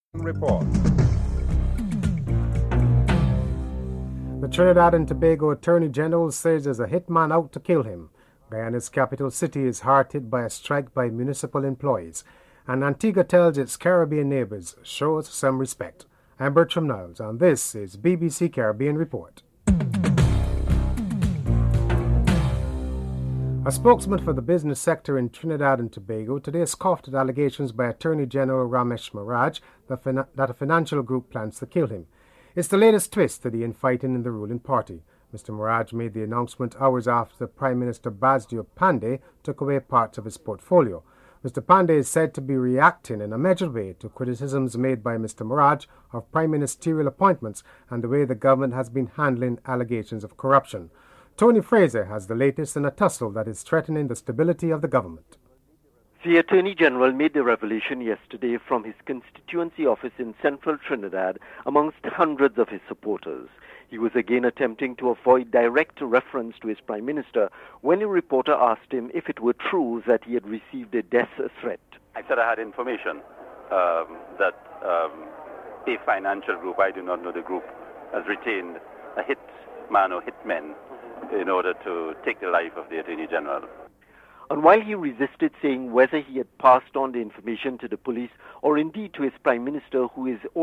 Prime Minister Lester Bird is interviewed (07:43-11:11)